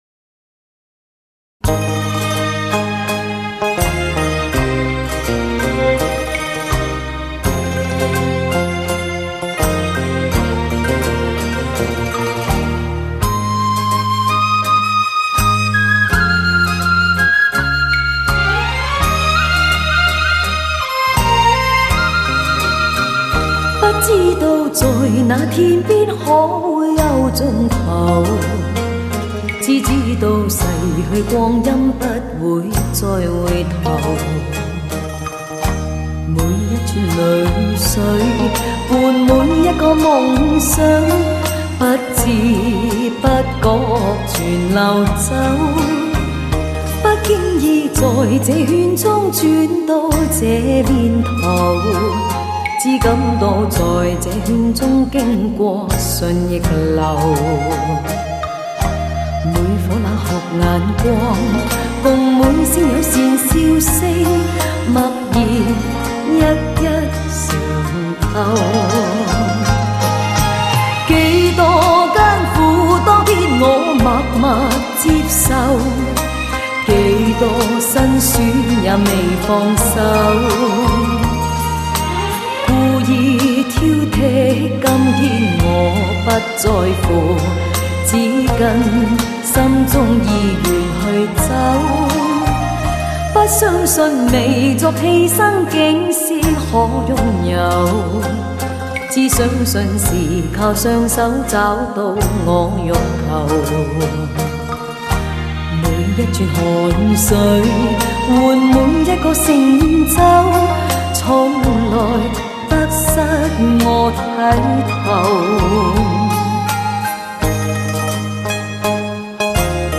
香港歌后 粤语流行经典